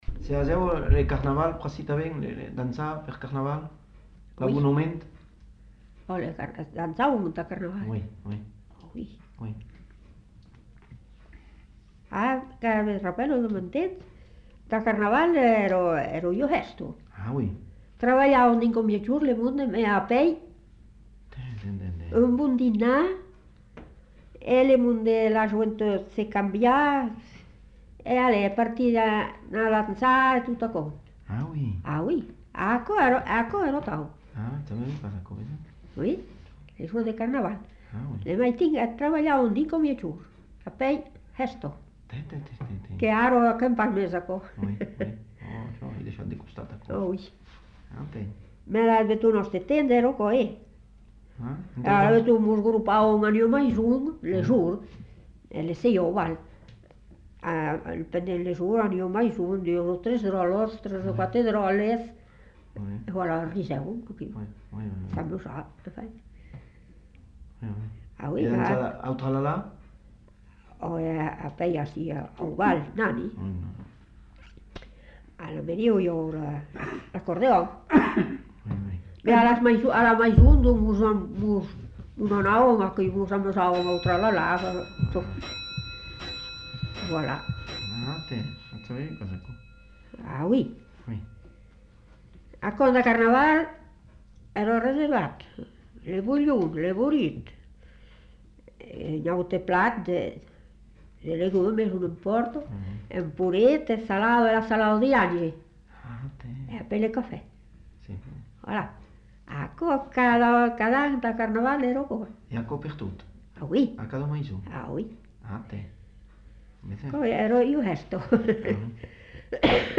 Lieu : Montadet
Genre : témoignage thématique
Instrument de musique : accordéon